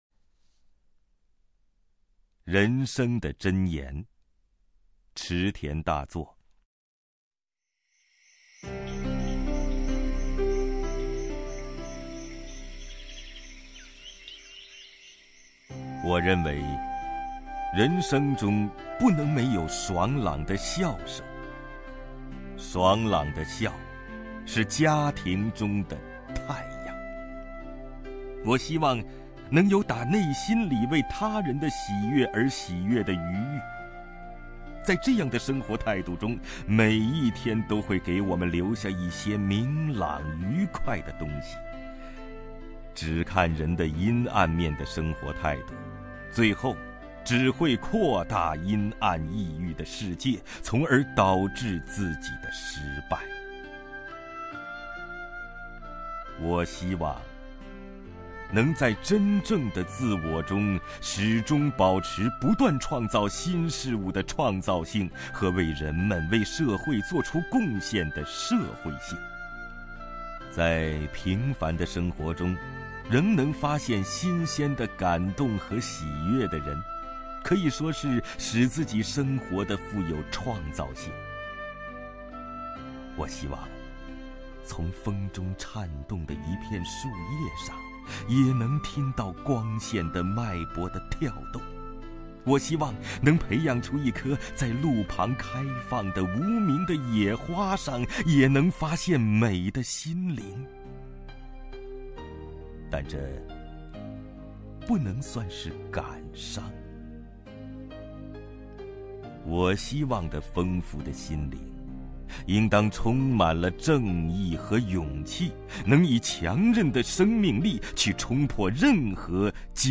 首页 视听 名家朗诵欣赏 王凯
王凯朗诵：《人生的箴言》(（日）池田大作)